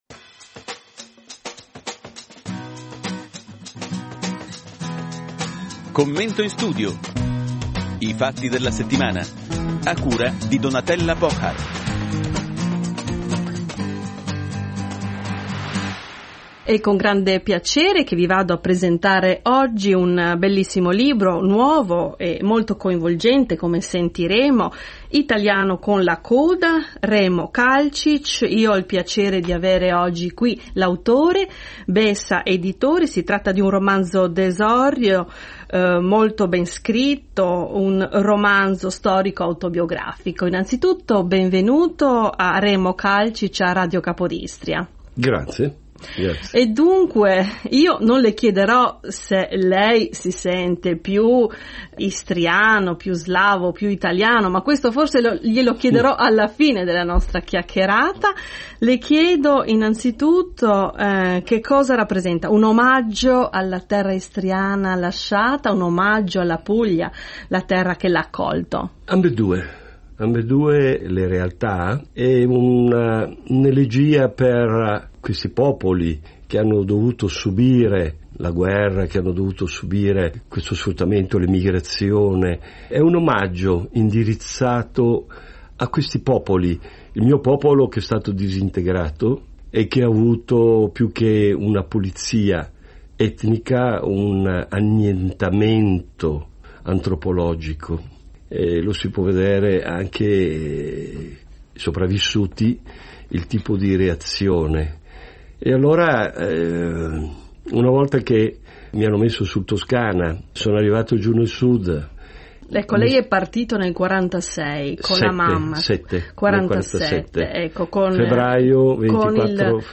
intervista in studio